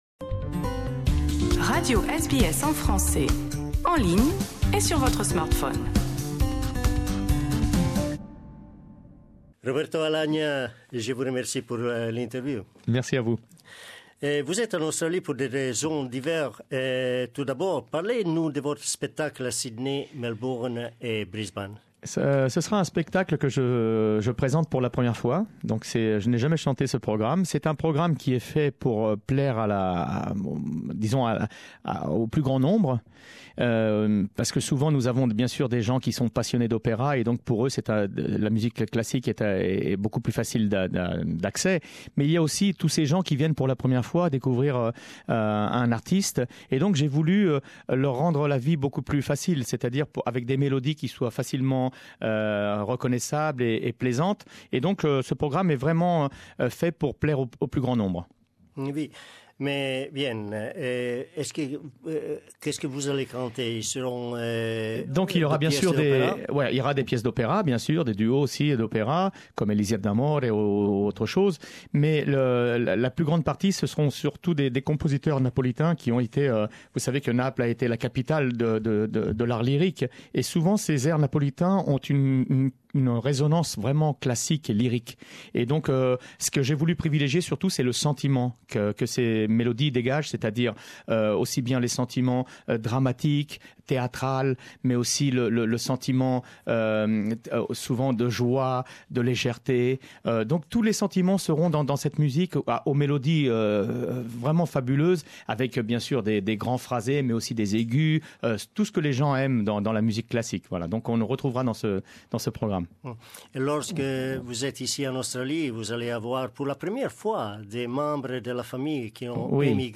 Rencontre avec Roberto Alagna qui donne des concerts en Australie en ce moment.